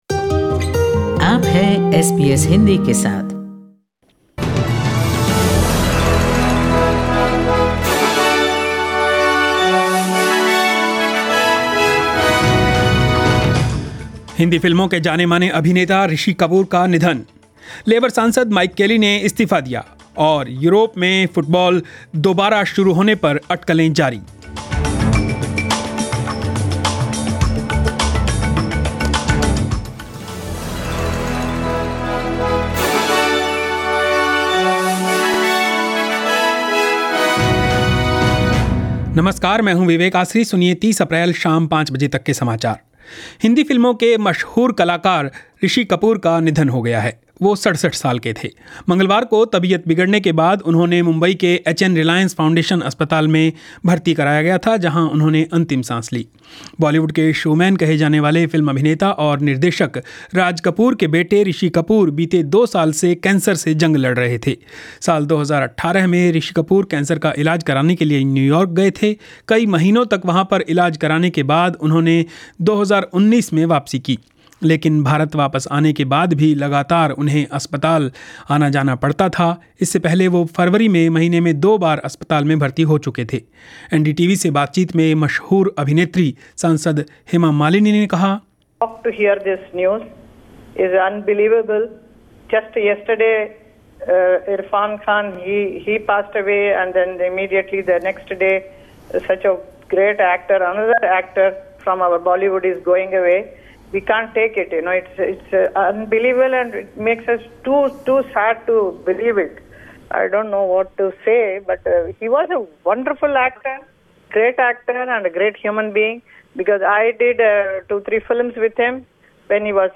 News in Hindi 30 April 2020